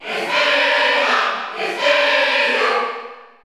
Category: Crowd cheers (SSBU) You cannot overwrite this file.
Rosalina_&_Luma_Cheer_Spanish_PAL_SSBU.ogg